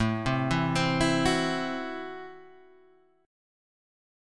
Am6 chord {5 3 4 5 5 5} chord